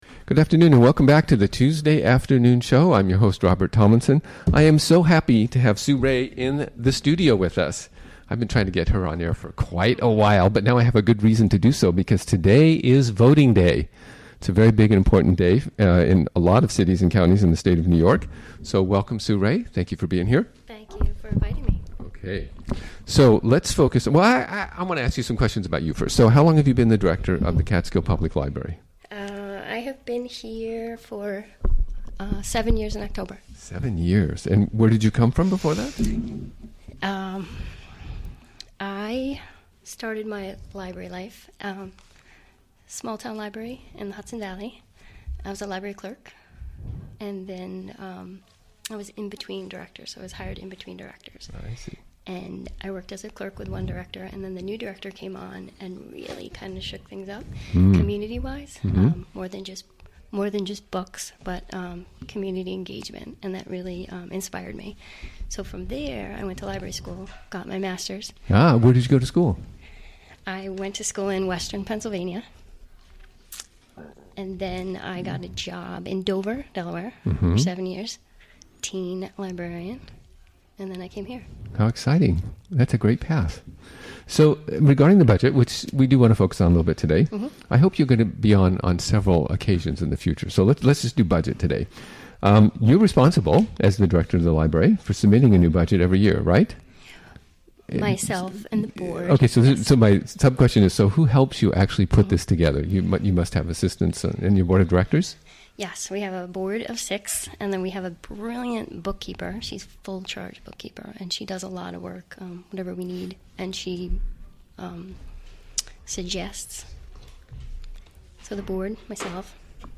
interview
Recorded live during the WGXC Afternoon Show of Tuesday, May 16, 2017.